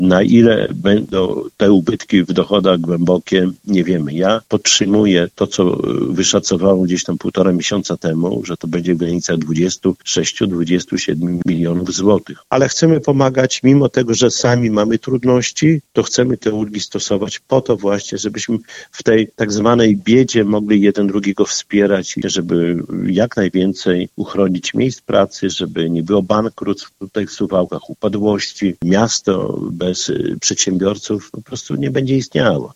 – Musimy jednak ratować przedsiębiorców i miejsca pracy. Miasto bez firm nie będzie istniało – dodał Czesław Renkiewicz.